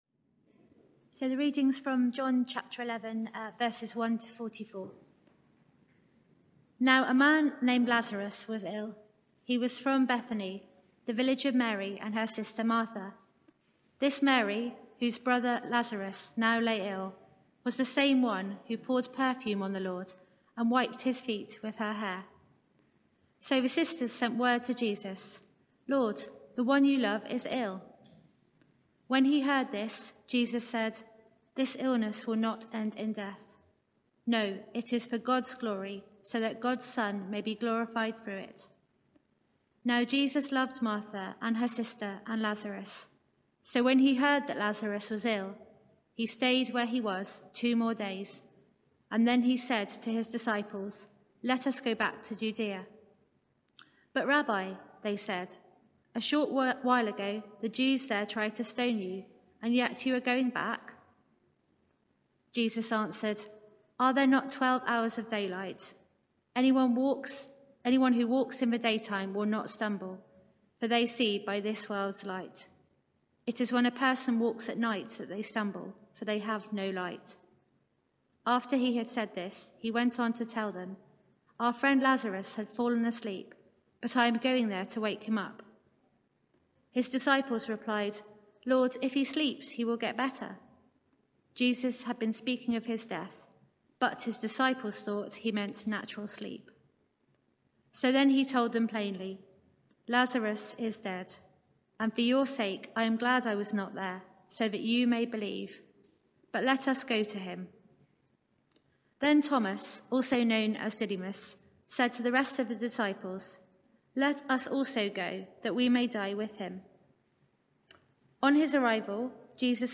Media for 6:30pm Service on Sun 04th Jul 2021 18:30 Speaker